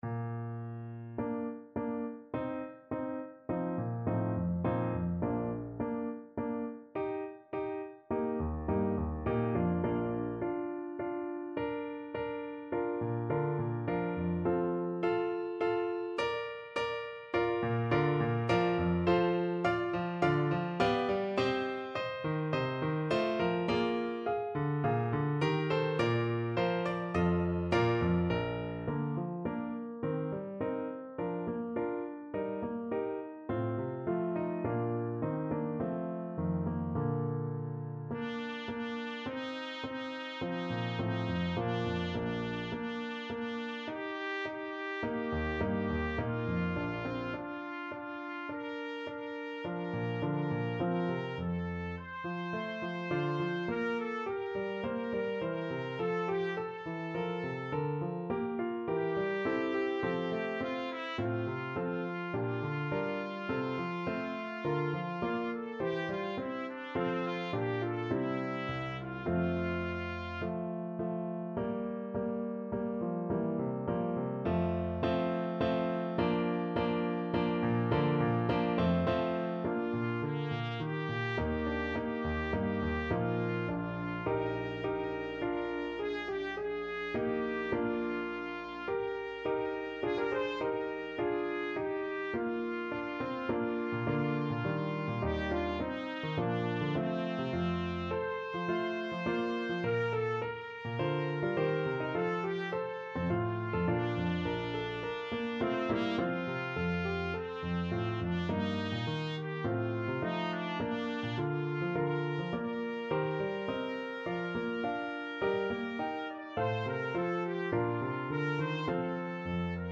Trumpet
Bb major (Sounding Pitch) C major (Trumpet in Bb) (View more Bb major Music for Trumpet )
4/4 (View more 4/4 Music)
Bb4-D6
Larghetto (=c.52)
Classical (View more Classical Trumpet Music)